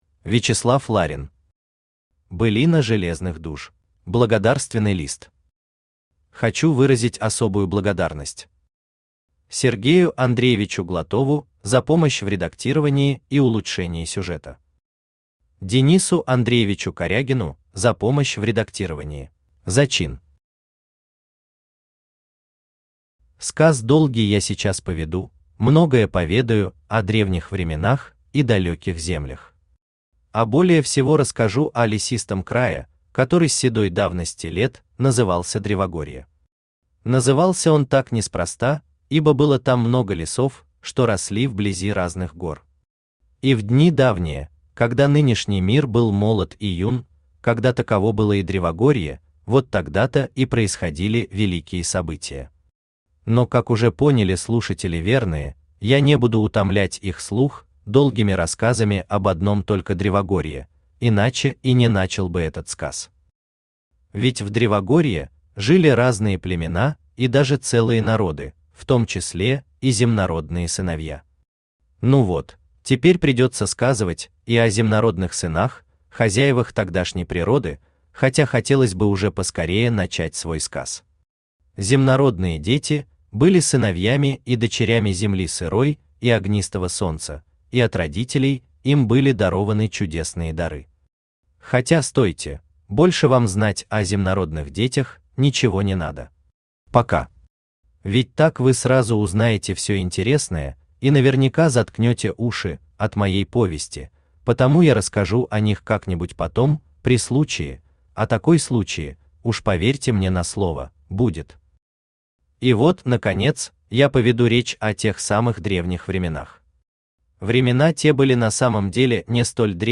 Аудиокнига Былина железных душ | Библиотека аудиокниг
Aудиокнига Былина железных душ Автор Вячеслав Ларин Читает аудиокнигу Авточтец ЛитРес.